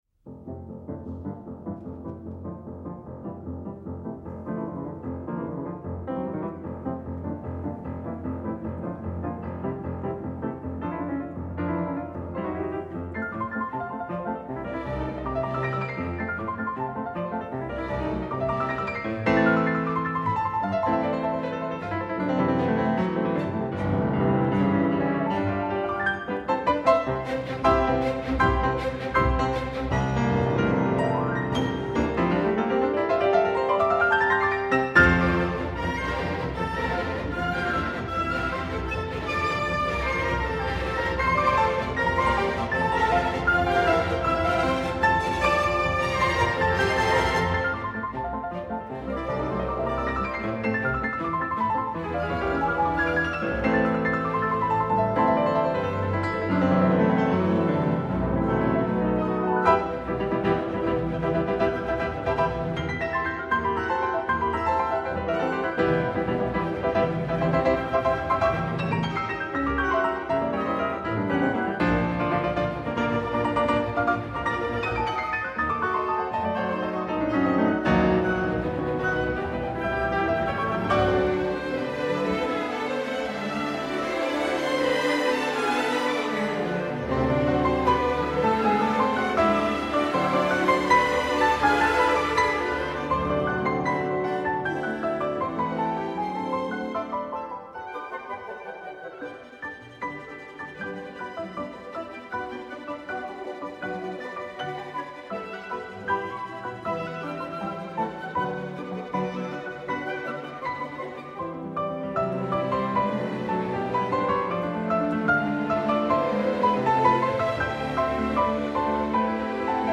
pianiste français trop rares